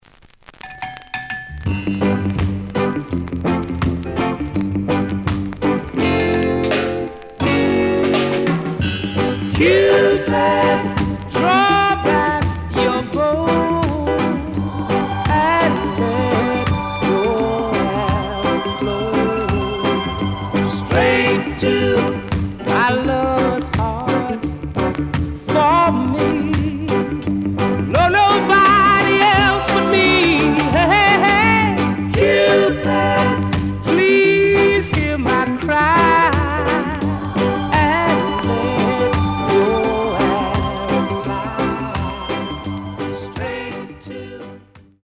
The audio sample is recorded from the actual item.
●Genre: Rock Steady
Some damage on both side labels. Plays good.)